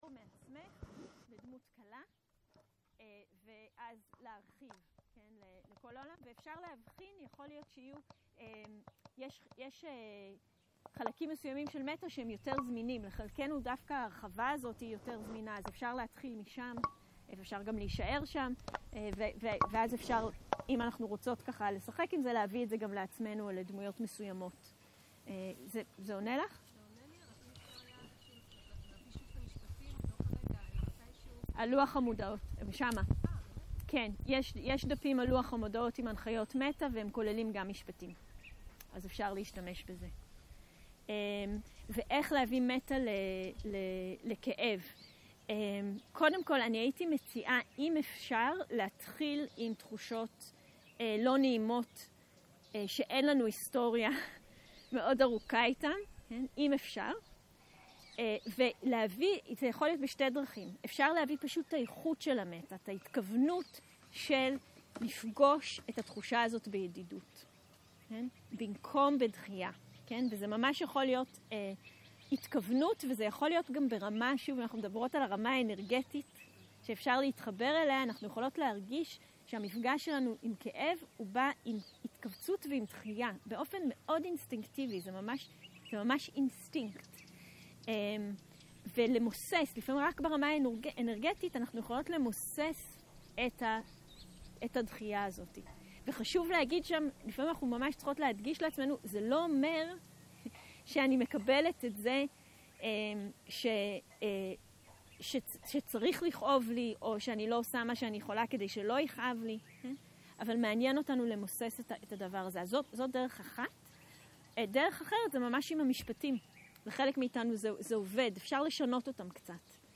בוקר - שאלות ותשובות